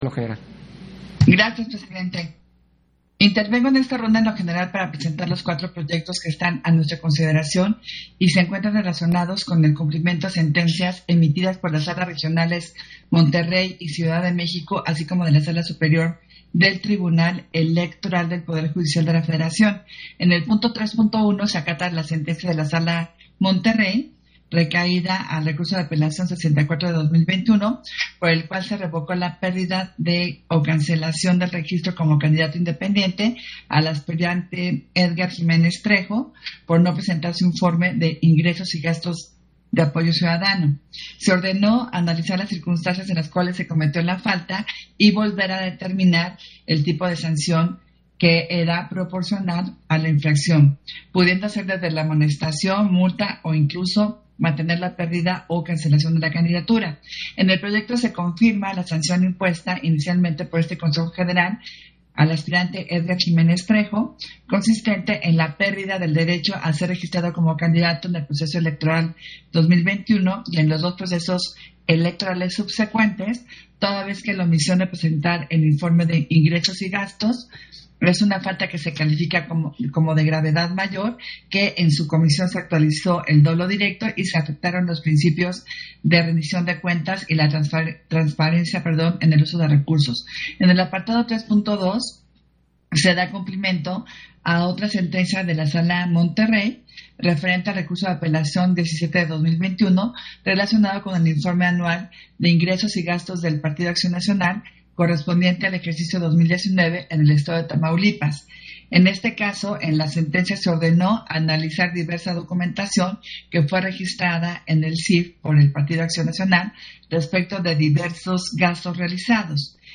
Intervención de Adriana Favela, en el punto 3 de la Sesión Extraordinaria, relativo al cumplimiento de sentencias del TEPJF en materia de fiscalización